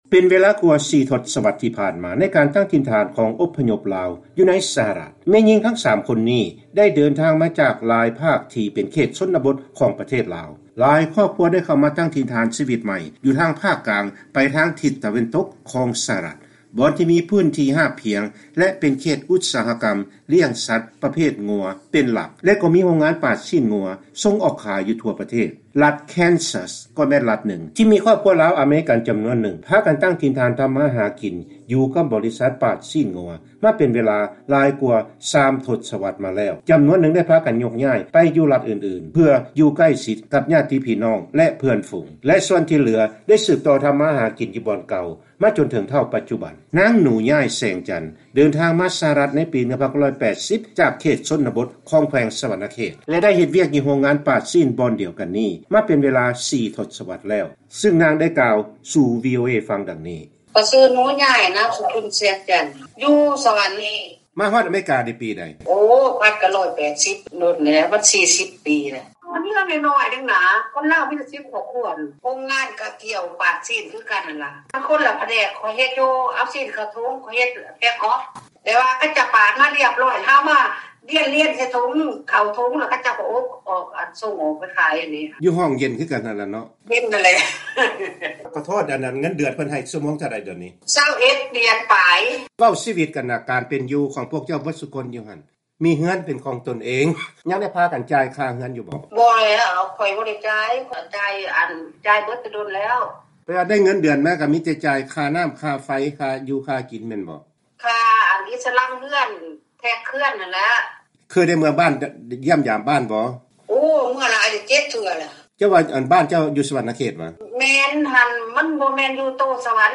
ເຊີນຟັງການໂອ້ລົມກັບ ສາມສະຕີລາວອາເມຣິກັນ ເຮັດວຽກຢູ່ໂຮງງານປາດຊີ້ນວງົວ